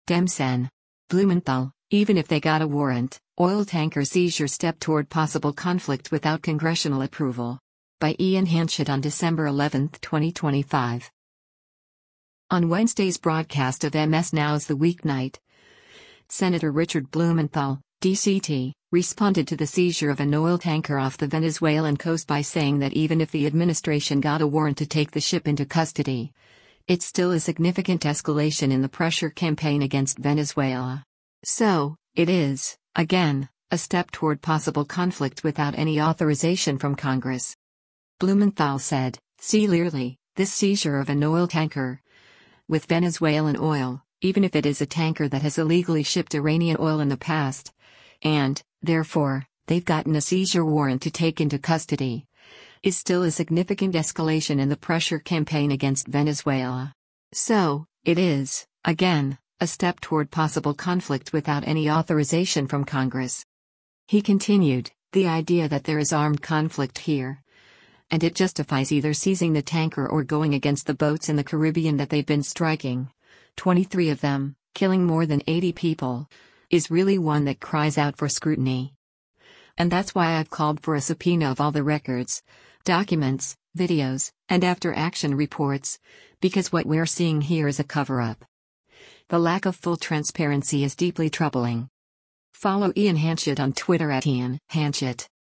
On Wednesday’s broadcast of MS NOW’s “The Weeknight,” Sen. Richard Blumenthal (D-CT) responded to the seizure of an oil tanker off the Venezuelan coast by saying that even if the administration got a warrant to take the ship into custody, it’s “still a significant escalation in the pressure campaign against Venezuela. So, it is, again, a step toward possible conflict without any authorization from Congress.”